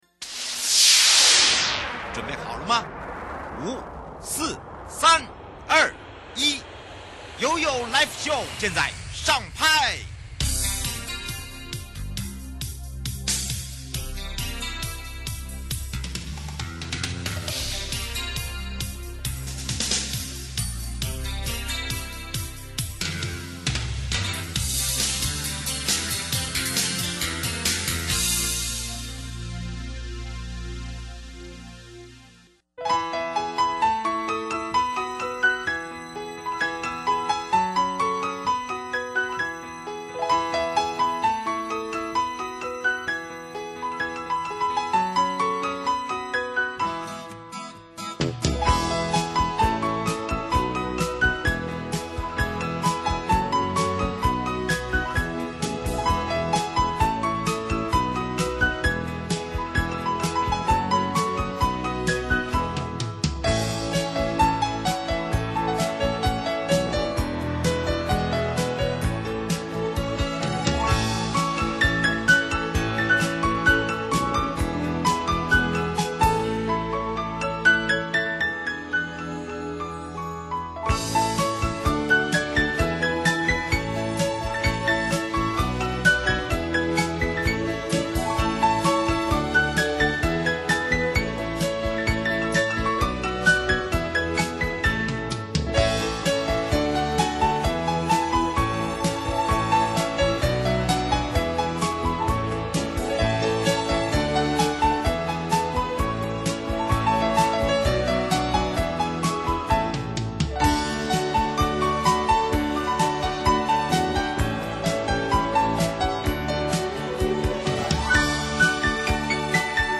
受訪者： 台灣高檢署林宏松檢察官 節目內容： 題目：竊案除報案外，要提出告訴嗎？